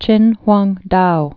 (chĭnhwängdou) also Chin·wang·tao (-wängtou)